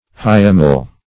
Search Result for " hyemal" : The Collaborative International Dictionary of English v.0.48: Hyemal \Hy*e"mal\, a. [L. hyemalis, or better hiemalis, fr. hyems, hiems, winter: cf. F. hy['e]mal.] Belonging to winter; done in winter.